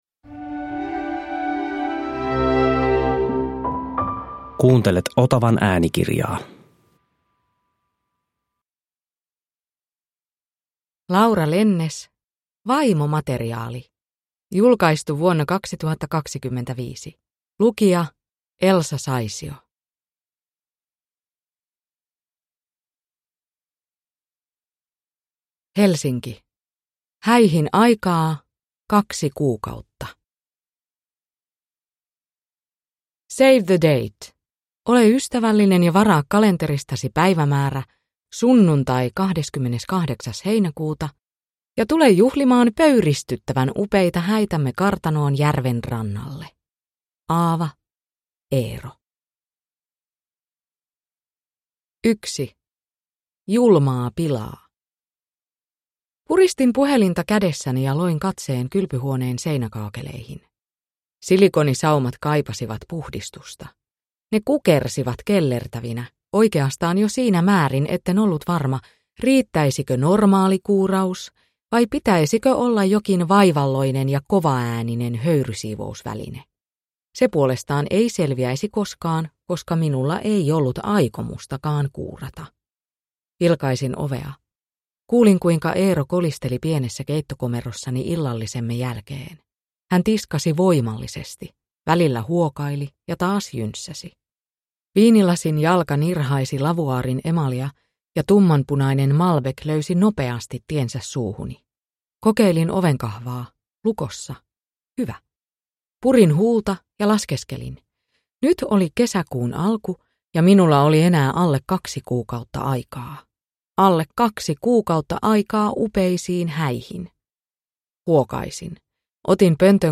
Vaimomateriaali (ljudbok) av Laura Lennes